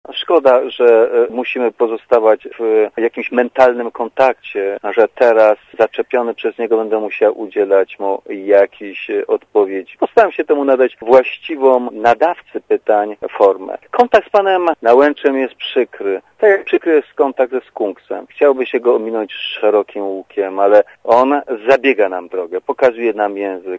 Dla radia ZET mówi Konstanty Miodowicz